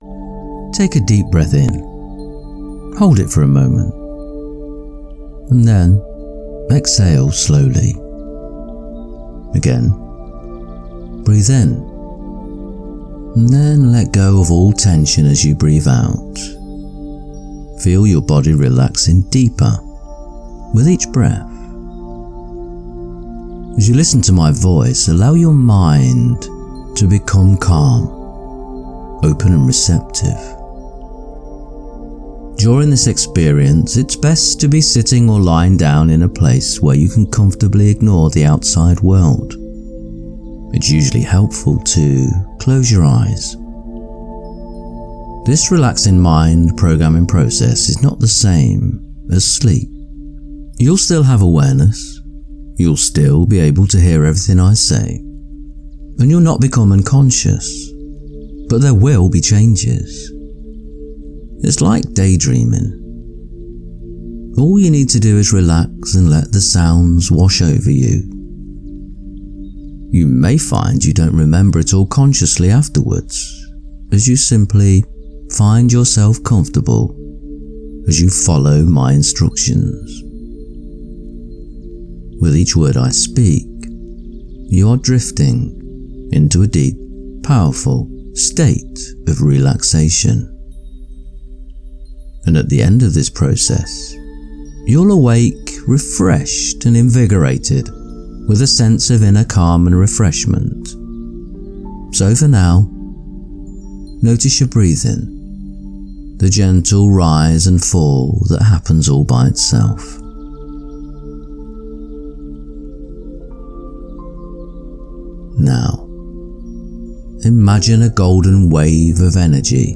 What you’ll experience: Guided visualization of your most happiest lifestyle.
Abundance affirmations embedded in calm narration.